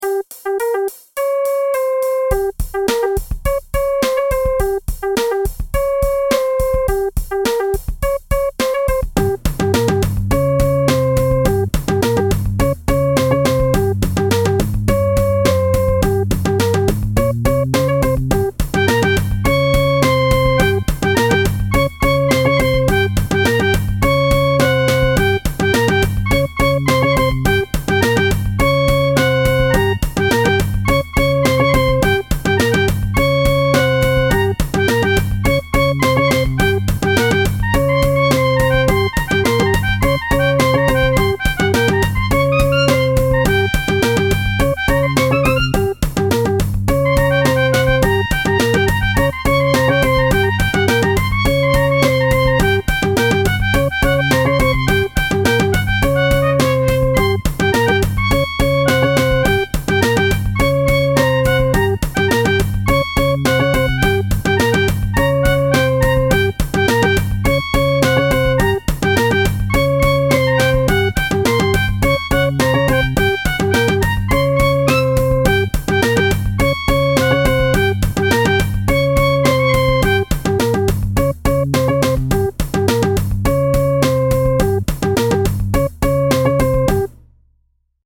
Pieza de Electro Jazz
Música electrónica
jazz
melodía
sintetizador